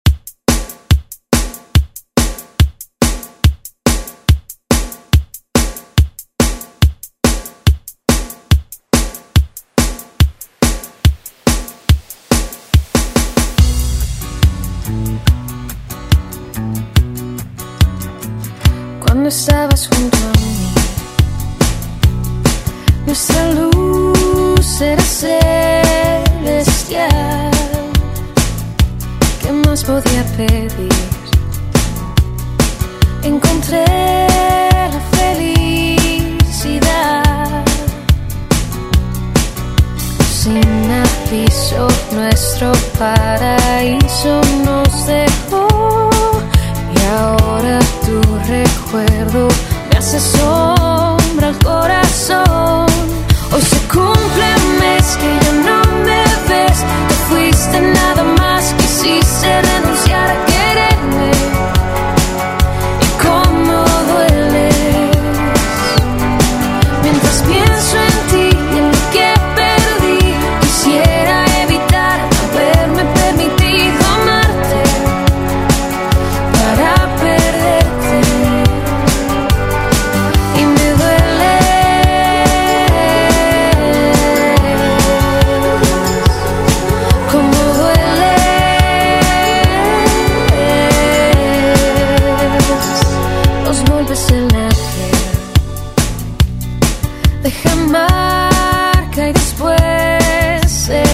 Genres: LATIN , RE-DRUM Version: Clean BPM: 142 Time